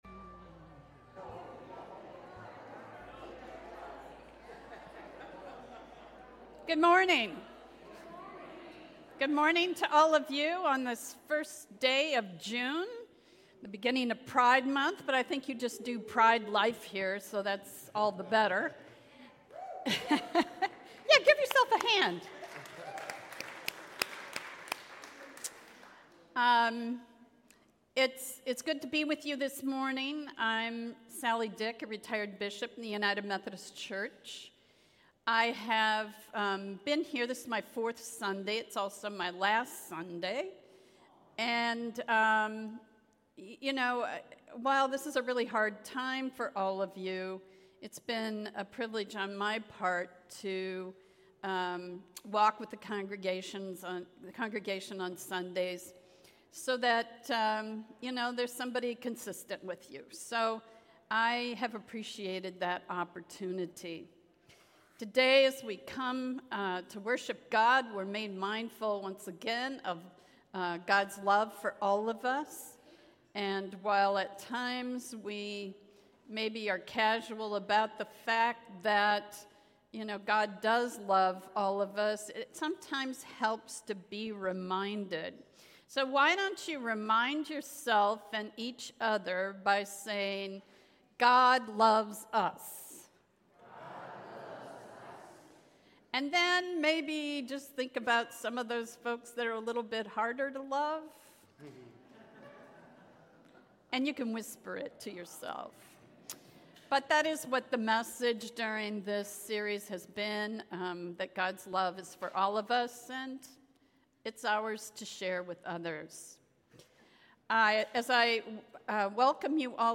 This Sunday we conclude our 3 week series at the Table called Agape focusing on Divine Love and we welcome guest Bishop Sally Dyck to share today’s Message.